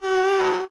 moan1.ogg